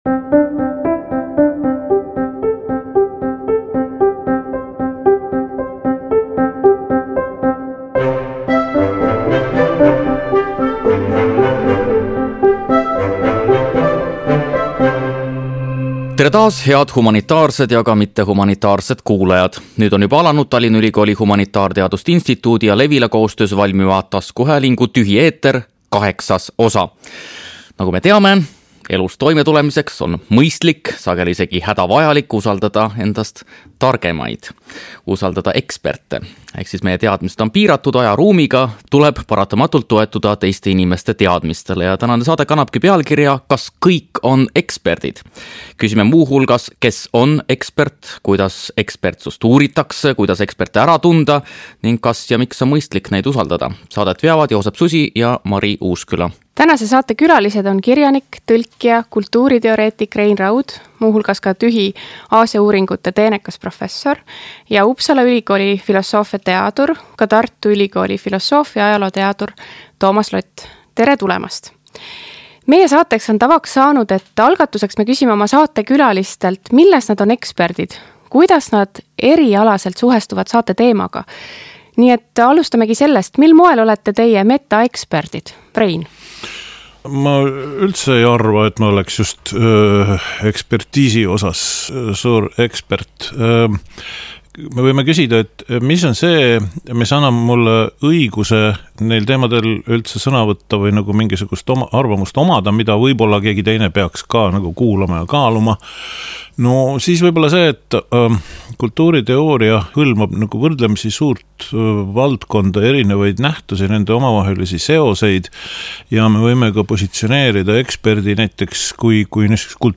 Kuidas eksperte ära tunda ning kas ja miks on mõistlik neid usaldada? Neil teemadel vestlevad kirjanik,.